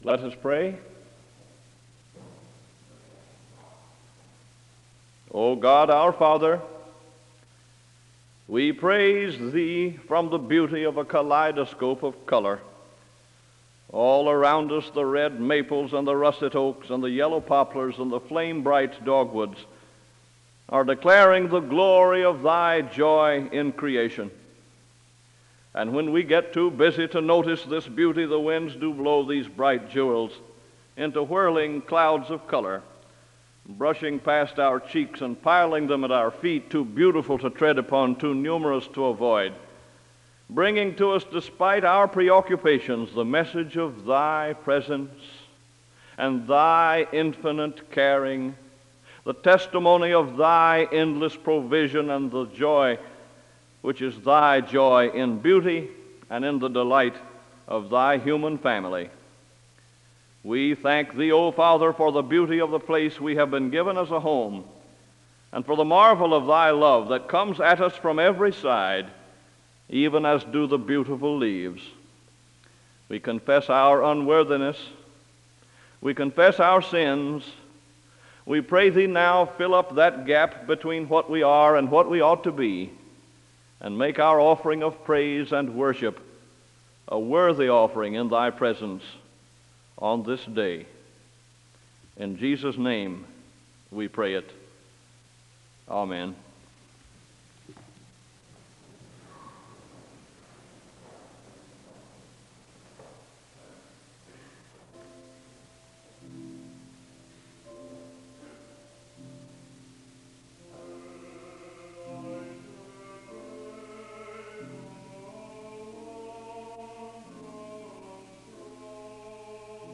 The service begins with a word of prayer (00:00-01:40). A student sings a song of worship (01:41-05:10).
The service ends with a word of prayer (24:09-24:35).